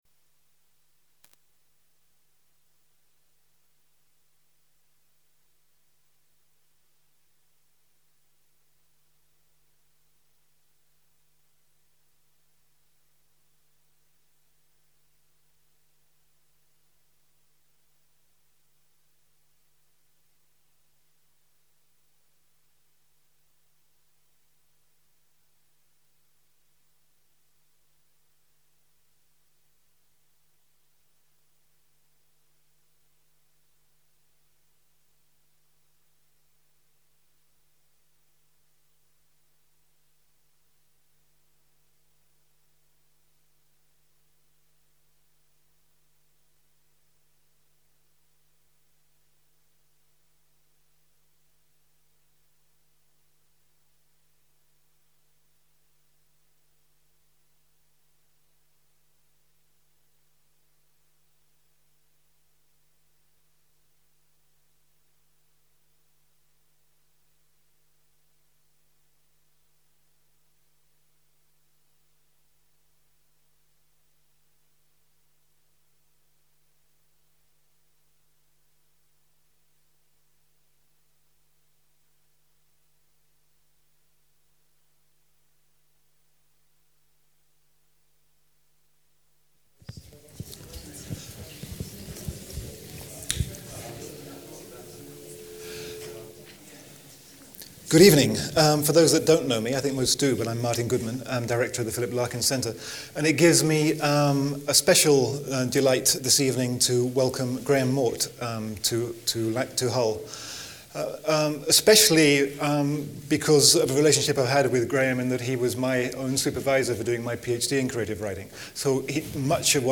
Poetry, short stories and radio drama – come and see how a writer dances between forms. Recording of an event held 22nd April 2010 in the Middleton Hall, University of Hull.